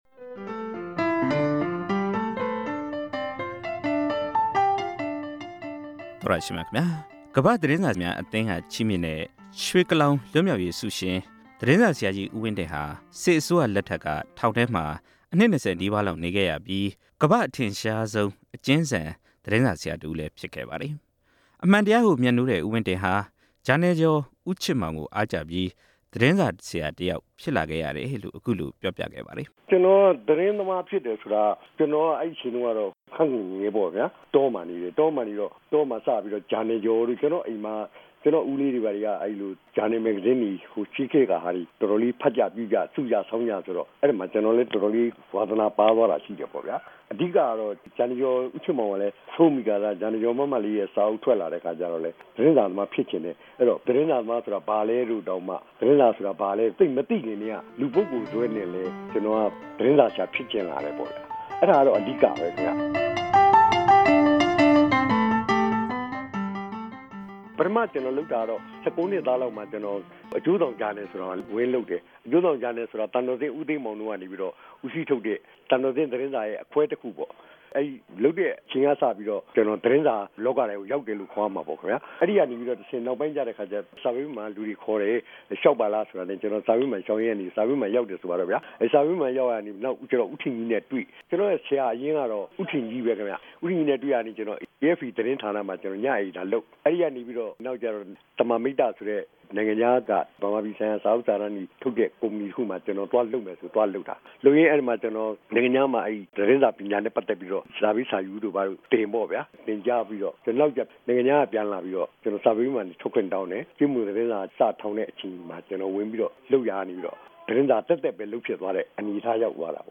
ဦးဝင်းတင် ကိုယ်တိုင်ပြောပြခဲ့တဲ့ ဘဝဖြတ်သန်းမှုအကြောင်း